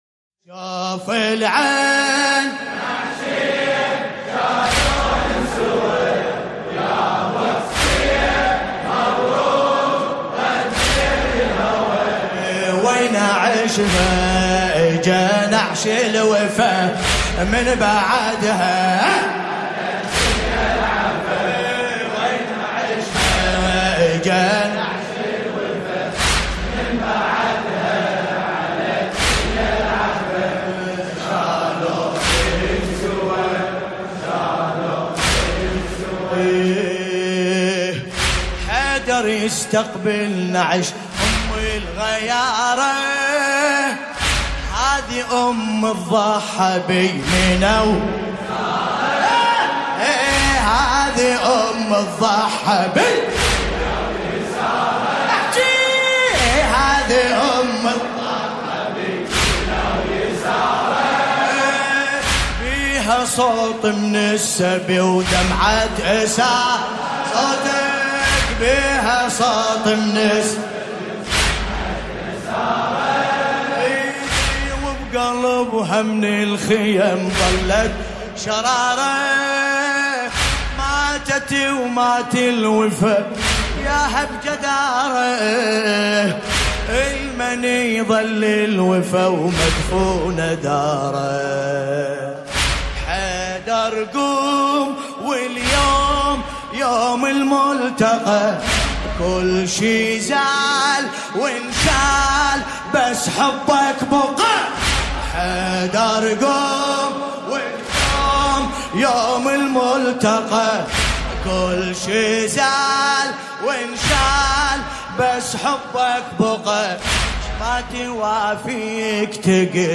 ليلة ١١ جمادى الثاني ١٤٣٩هـ | كربلاء